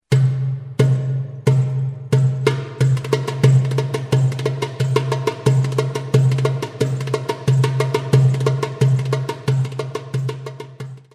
Both CDs contain a variety of all Percussion Music